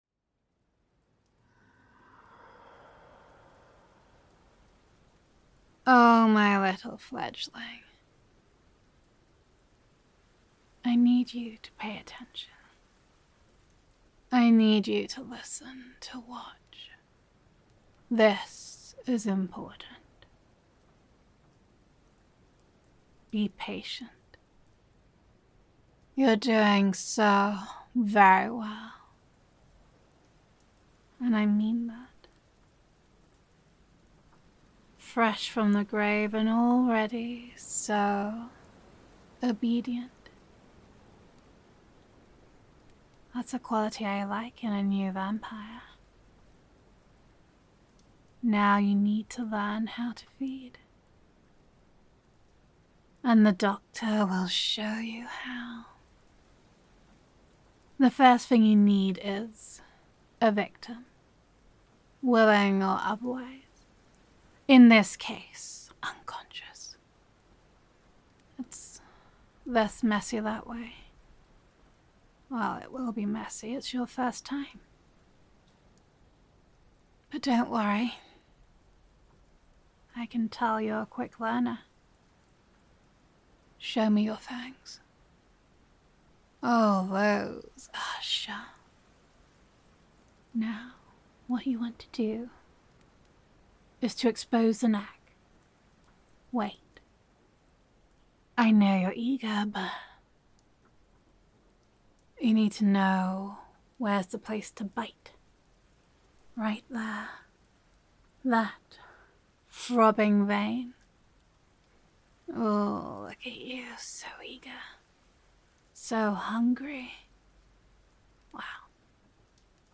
[F4A] Working Dinners [Vampire Therapist][Fledging Listener][Where to Bite][Time for Dinner][Gender Neutral][Being a Vampire Therapist Has Its Perks]